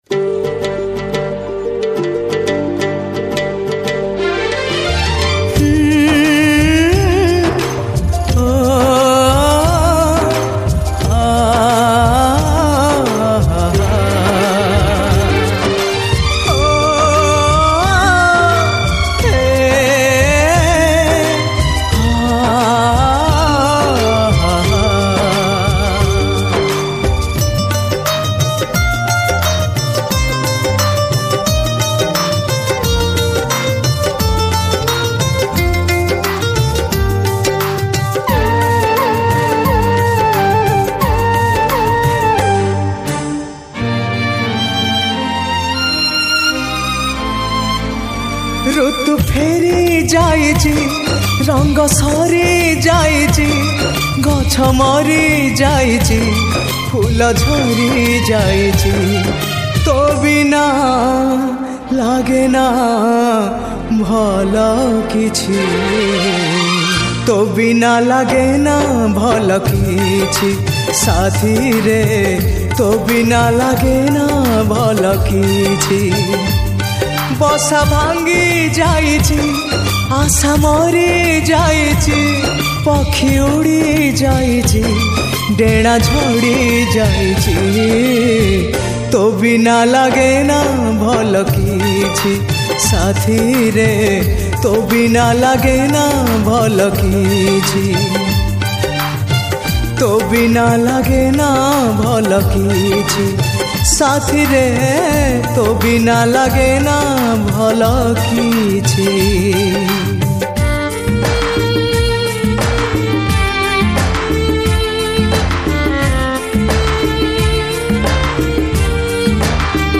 Sad Song